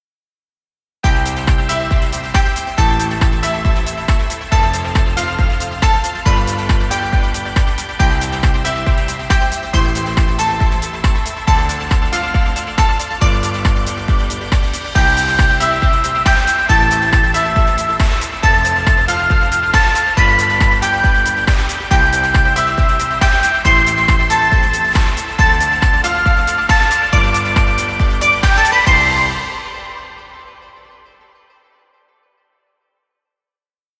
Children happy music. Background music Royalty Free.